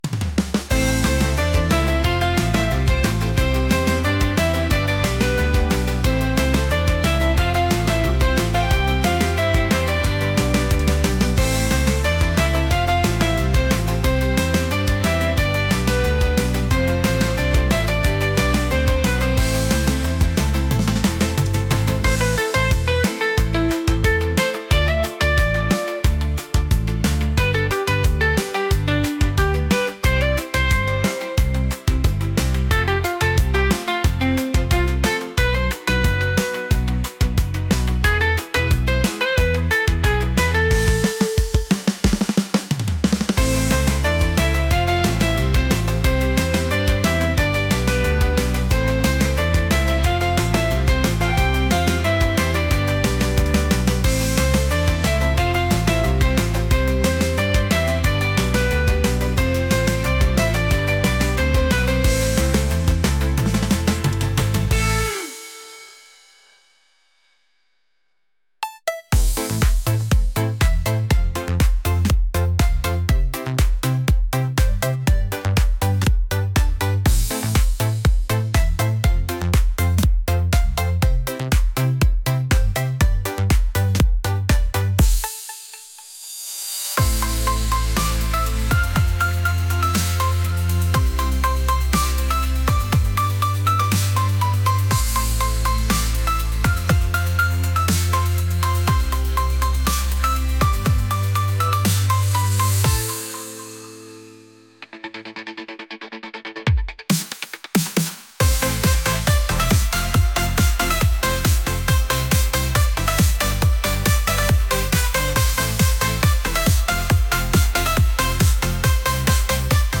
energetic | catchy | pop